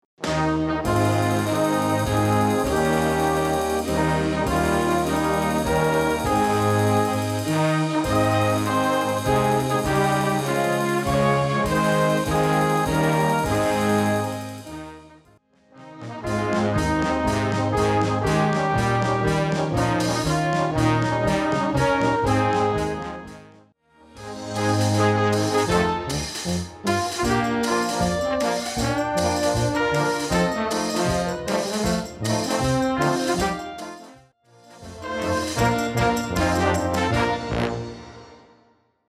Besetzung: Blasorchester
3 Strophen: Choral - Marsch - Dixie